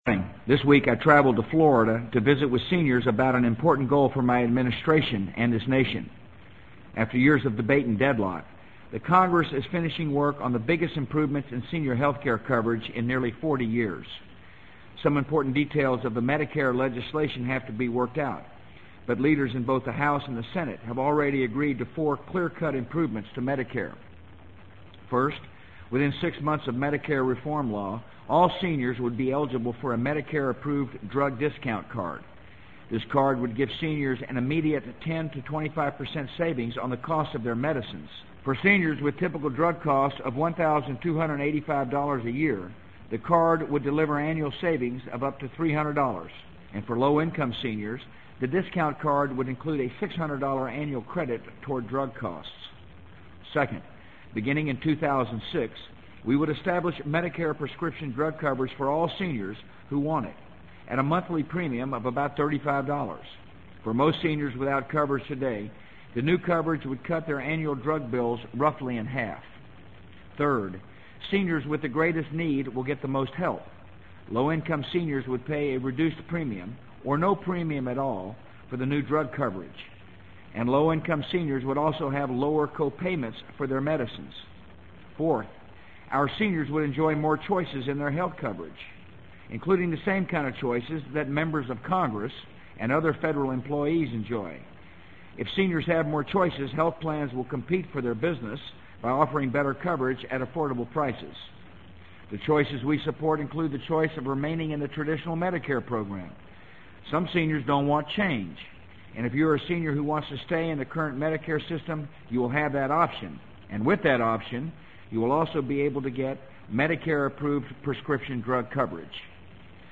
【美国总统George W. Bush电台演讲】2003-11-15 听力文件下载—在线英语听力室